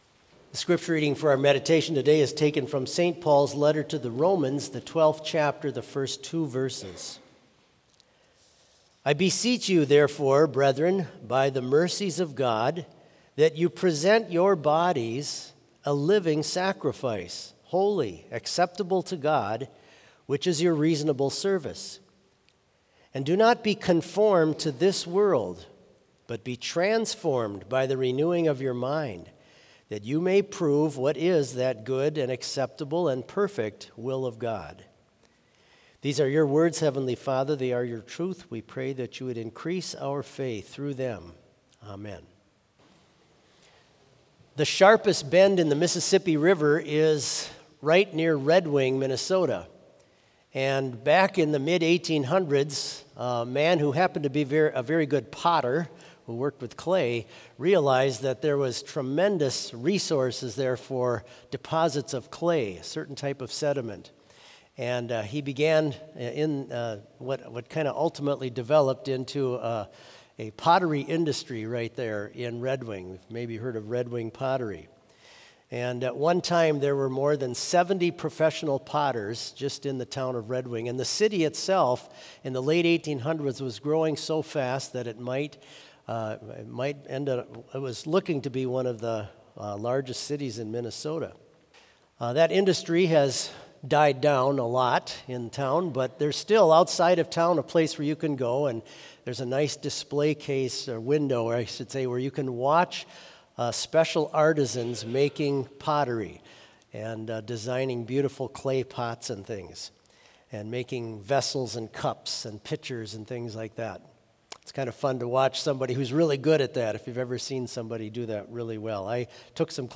Complete service audio for Chapel - Friday, March 14, 2025